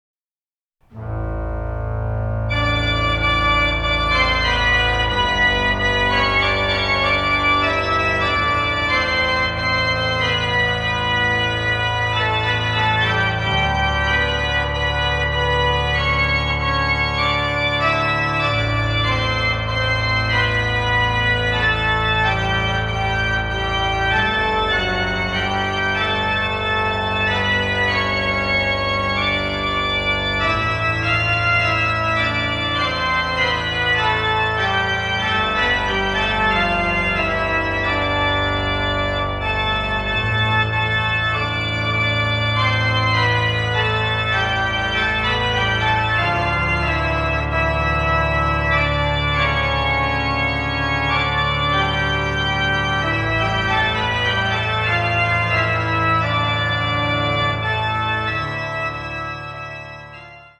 colorful orchestral tapestry is a true exotic delight.